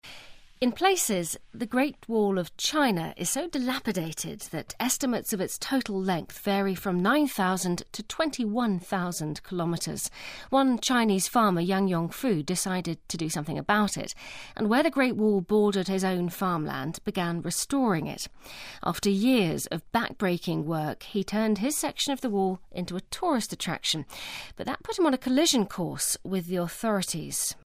【英音模仿秀】修长城的农民 听力文件下载—在线英语听力室